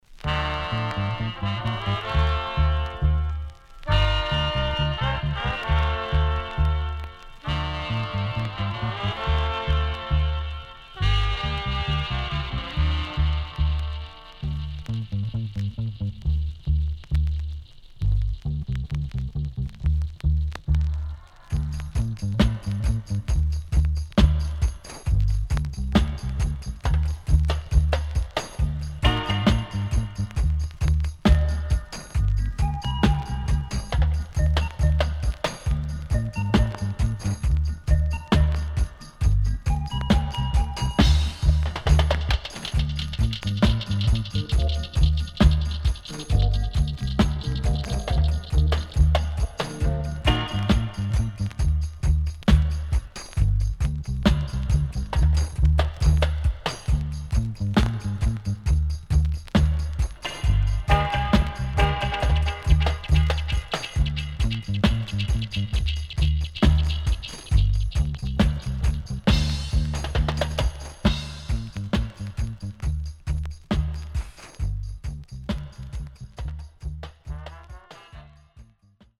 HOME > DUB
SIDE A:全体的にノイズ入ります。